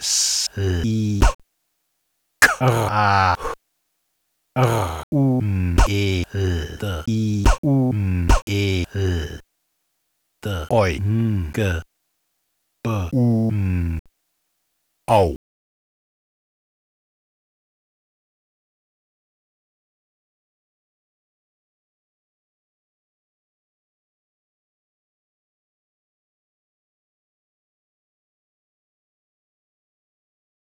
phonetic typewriter comic
Etwas Urzeitliches, aber nicht im herkömmlichen Comic-Denkschema, das Godzillas und Saurier in verborgenen Urwäldern sehen möchte. Hier lernt die Comicsprache sprechen, die Onomatopoesie entringt sich schönheitglänzend dem Chaos, um es mit Klopstock zu sagen.